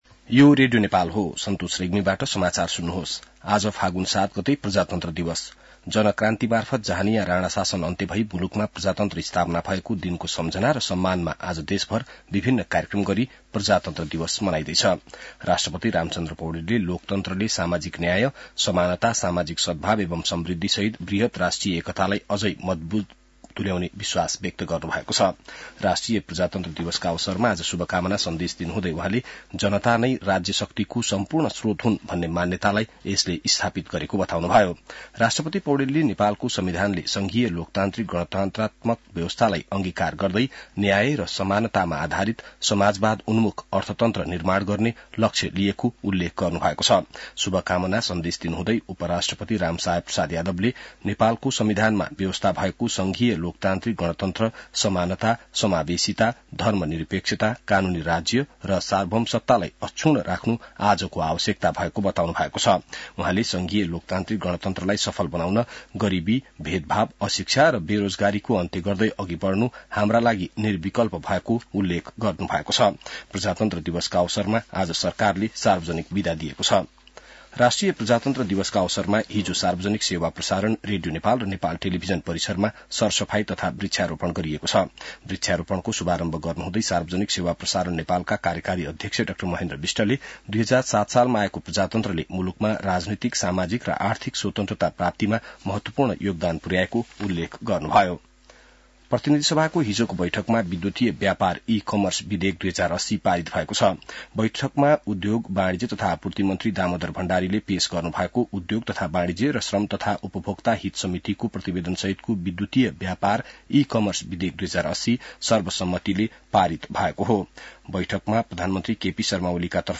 बिहान ६ बजेको नेपाली समाचार : ८ फागुन , २०८१